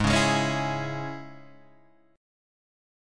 G#sus4#5 Chord